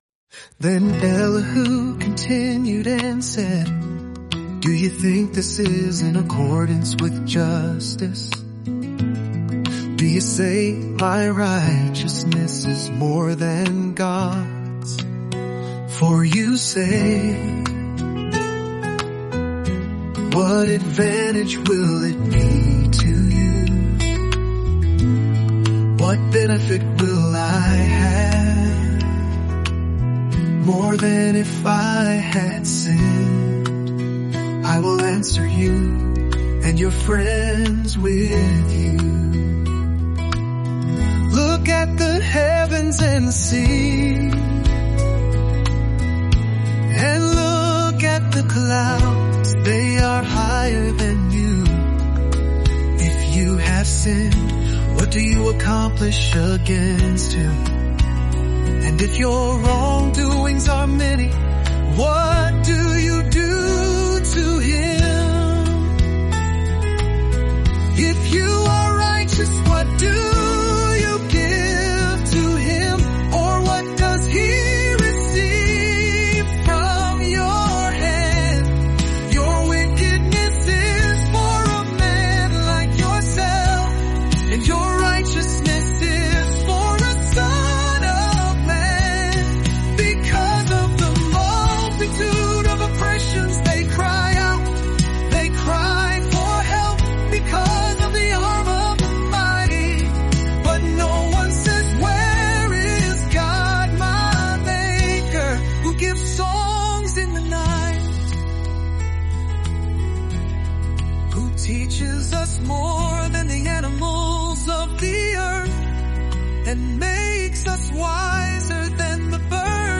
Walk with Job through his journey of deep suffering, honest questions, and steadfast faith in just 14 days through word-for-word Scripture songs.